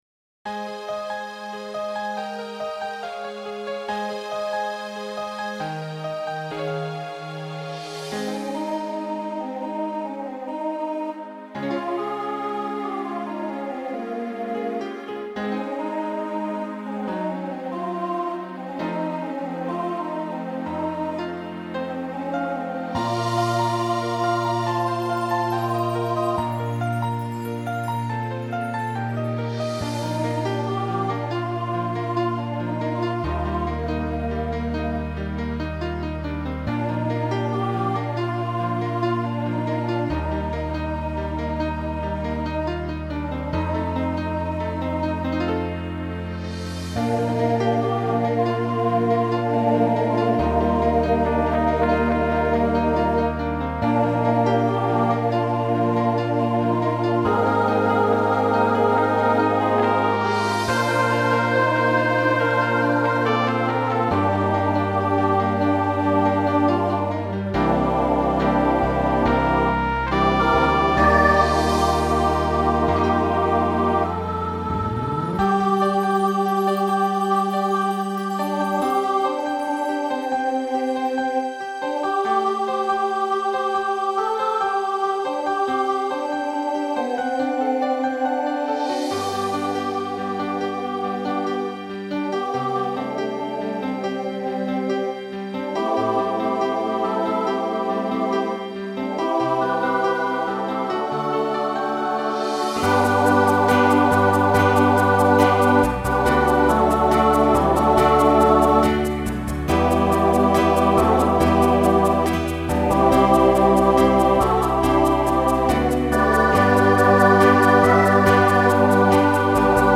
Voicing SATB Instrumental combo Genre Pop/Dance
2010s Show Function Ballad